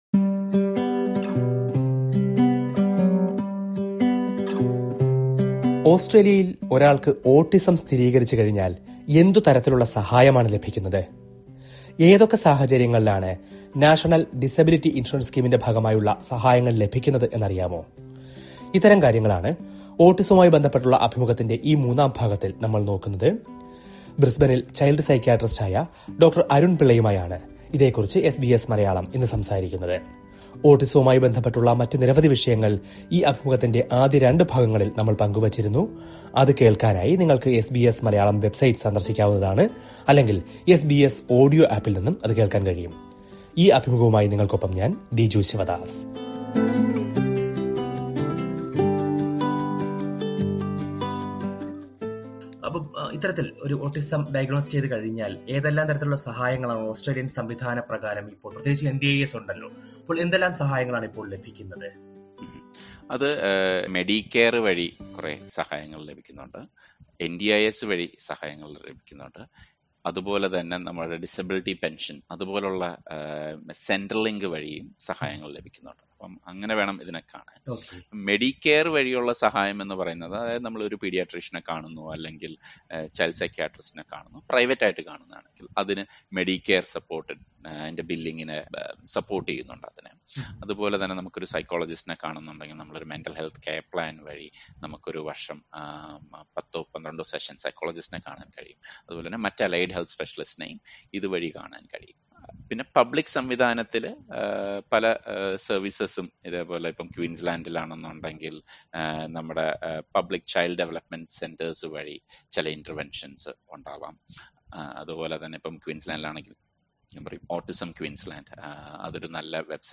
ഒരു കുട്ടിക്കോ, അല്ലെങ്കിൽ മുതിര്‍ന്നവർക്കോ ഓട്ടിസം സ്ഥിരീകരിച്ചുകഴിഞ്ഞാല്‍ ഓസ്‌ട്രേലിയയില്‍ ഏതെല്ലാം തരത്തിലുള്ള സഹായങ്ങളും, തെറാപ്പികളുമാണ് ലഭ്യമാകുന്നത് എന്നറിയാമോ? അക്കാര്യം കൂടി ഈ അഭിമുഖ പരമ്പരയുടെ മൂന്നാം ഭാഗത്തില്‍ നമ്മള്‍ പരിശോധിക്കുകയാണ്.